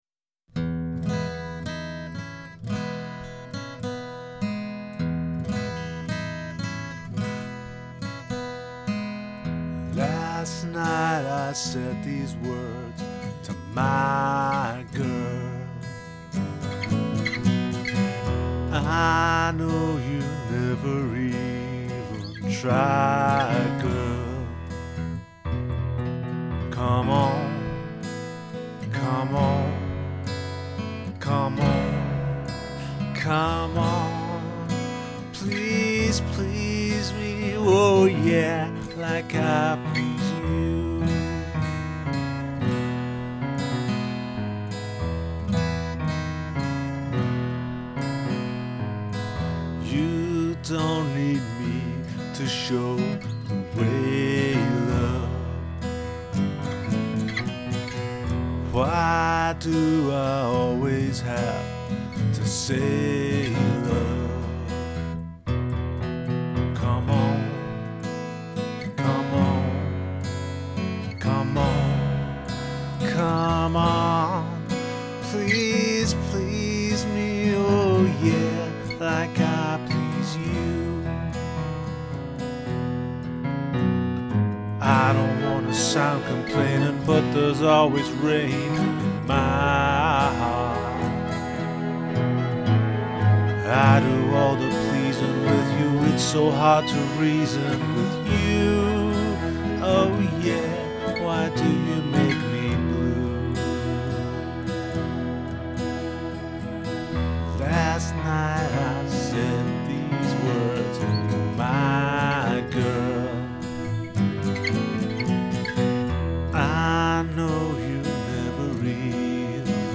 guitar and vocal